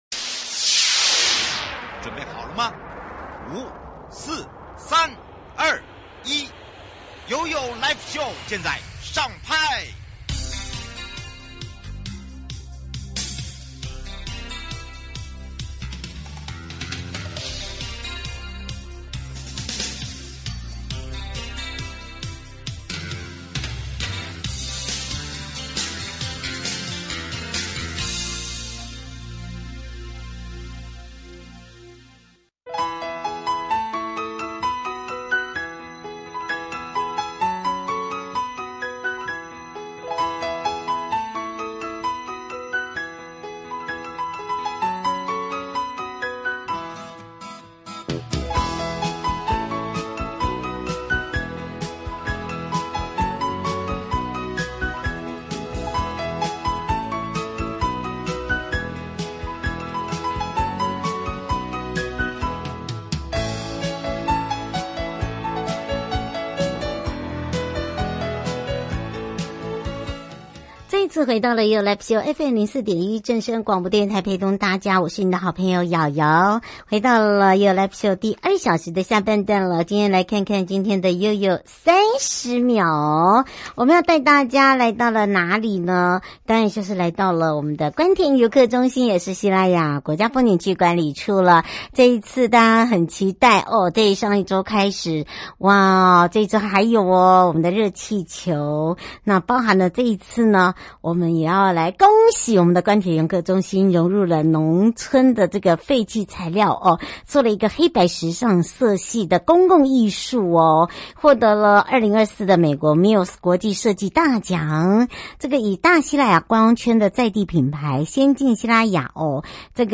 受訪者： 花東縱谷管理處